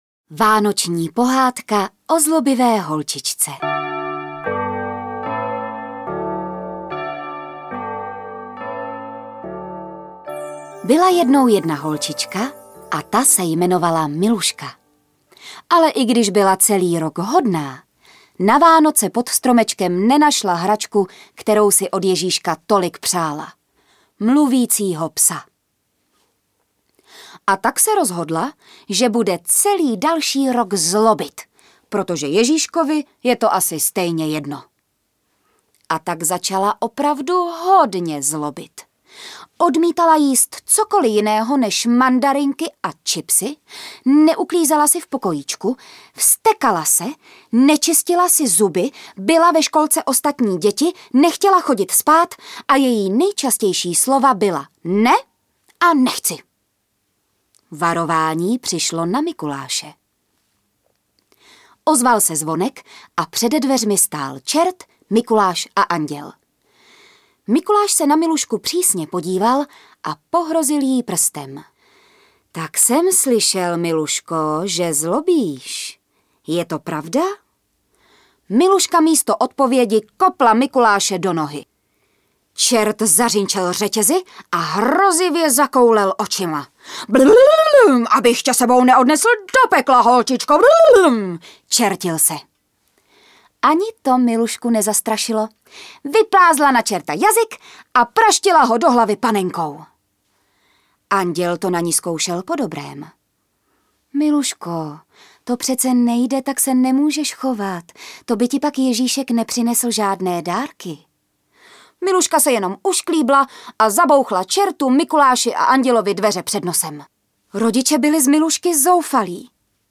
Výběr klasických pohádek s vánoční a zimní tématikou v podání známých českých herců. Hlasy Josefa Somra, Dany Morávkové, Jany Bouškové, Václava Vydry a dalších interpretů vykouzlí pravou vánoční pohádkovou atmosféru
Ukázka z knihy